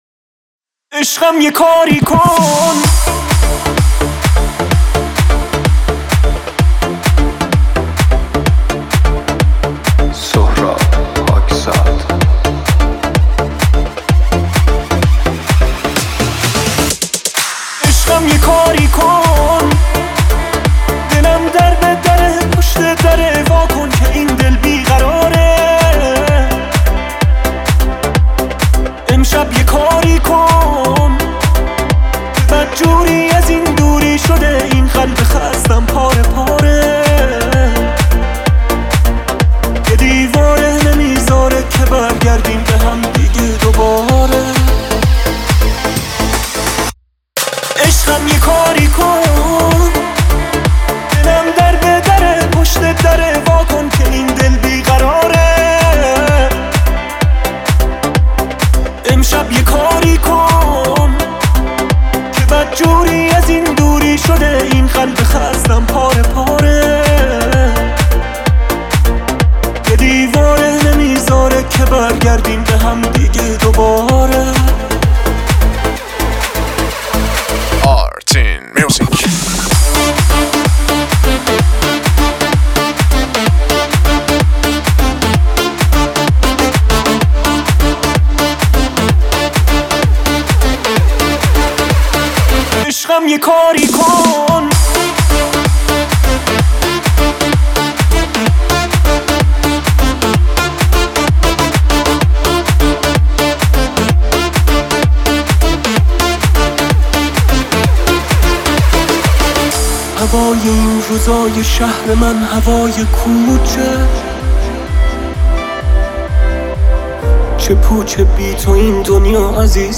Club Mix